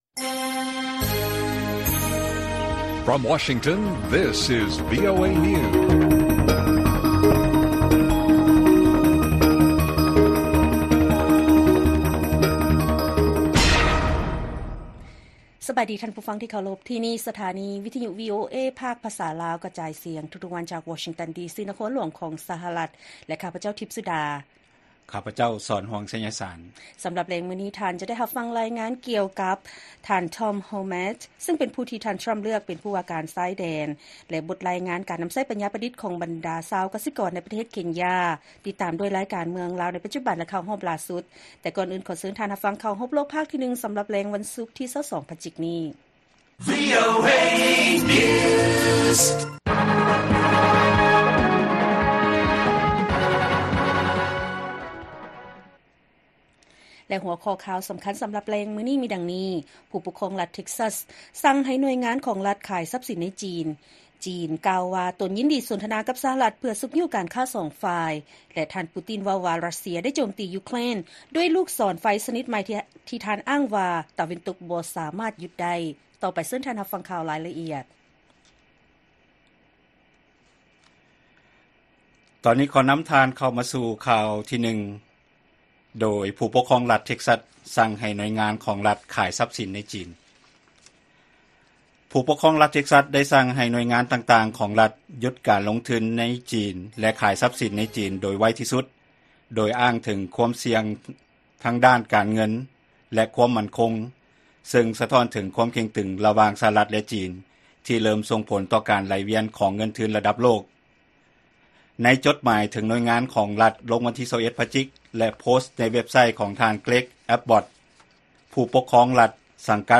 ວີໂອເອພາກພາສາລາວ ກະຈາຍສຽງທຸກໆວັນ, ຫົວຂໍ້ຂ່າວສໍາຄັນສໍາລັບແລງມື້ນີ້ ມີດັ່ງນີ້: ຜູ້ປົກຄອງລັດ ເທັກຊັສ ສັ່ງໃຫ້ໜ່ວຍງານຂອງລັດຂາຍຊັບສີນໃນຈີນ, ຈີນກ່າວວ່າ ຕົນຍິນດີສົນທະນາກັບ ສຫລ ເພື່ອຊຸກຍູ້ການຄ້າສອງຝ່າຍ, ແລະ ທ່ານ ປູຕິນ ເວົ້າວ່າ ຣັດເຊຍ ໂຈມຕີຢູເຄຣນດ້ວຍລູກສອນໄຟຊະນິດໃໝ່.